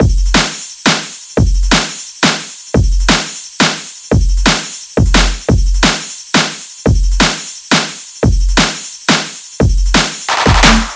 咒语
描述：由我自己设计的短暂魔法施法音效。
标签： 爆炸 冲击波 功能强大 充满活力 破坏 魔术 拼写
声道立体声